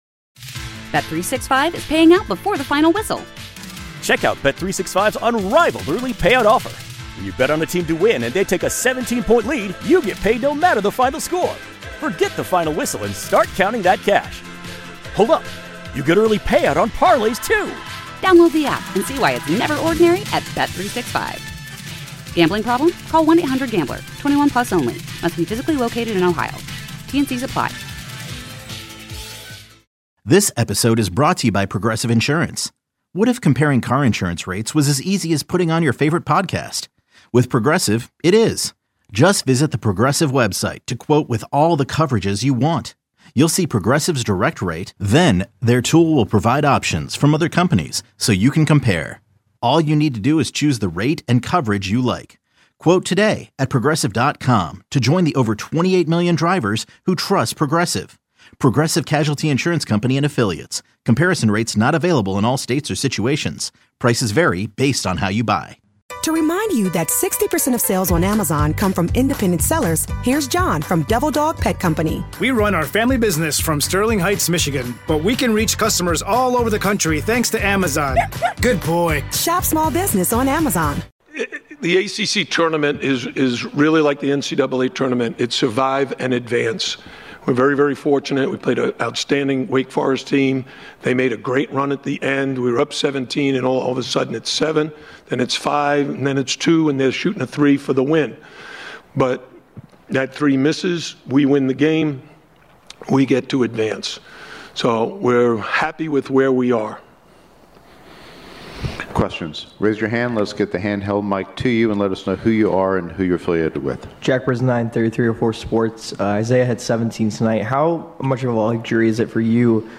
Canes Post Game Press Conference 3-9-23